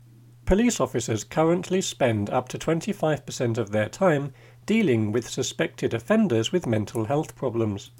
DICTATION 7